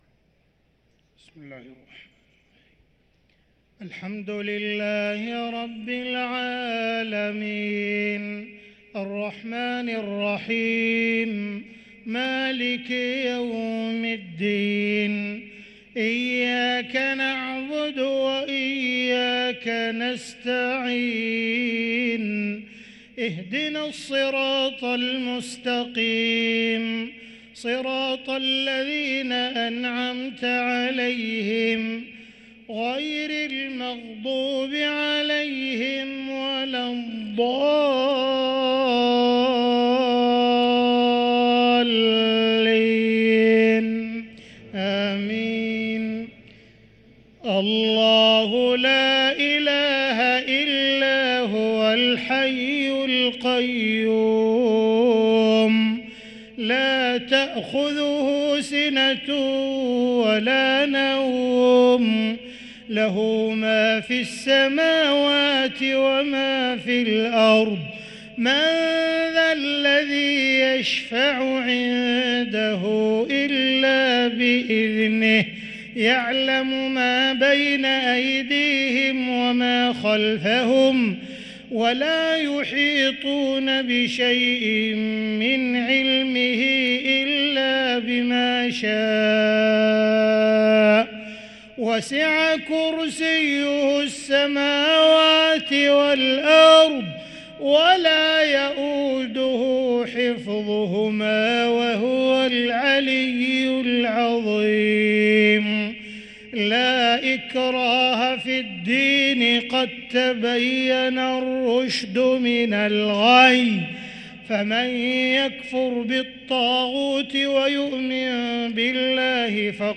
صلاة المغرب للقارئ عبدالرحمن السديس 13 رمضان 1444 هـ
تِلَاوَات الْحَرَمَيْن .